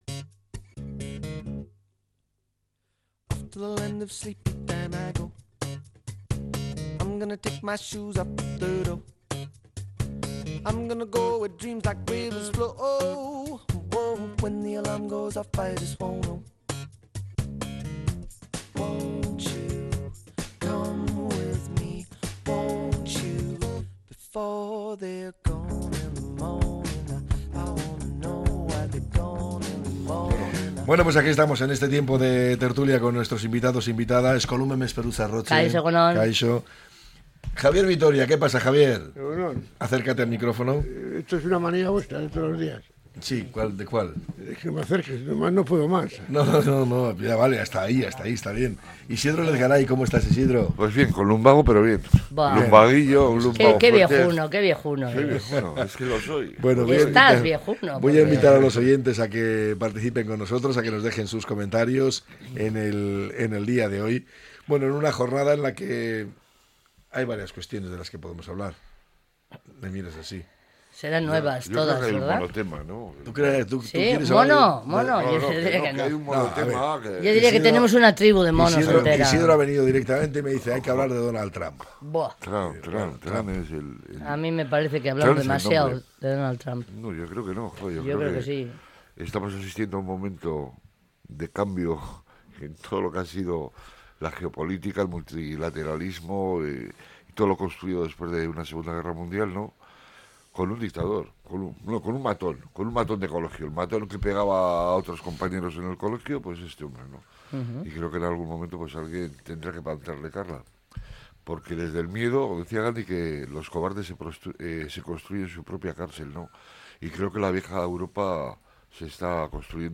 La tertulia 25-02-25.